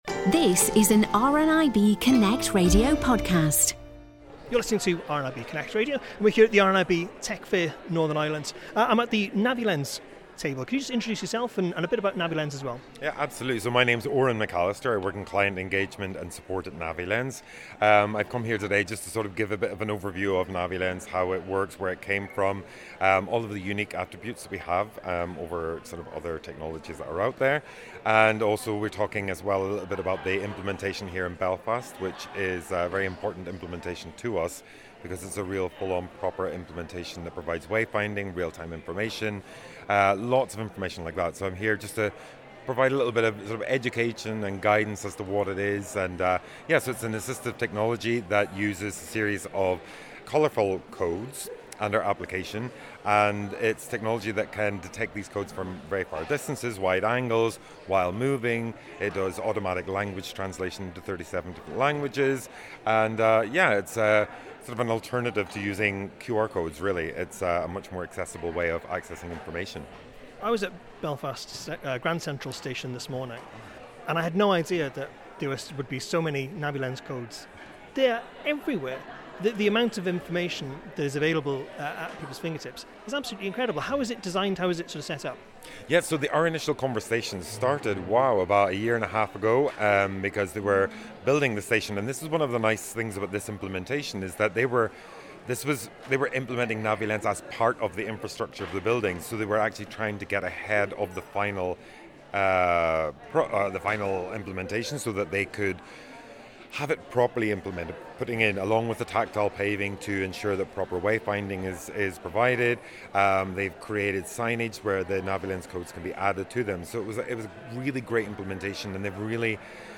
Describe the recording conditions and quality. RNIB NI Tech Fair 2025 15 – NaviLens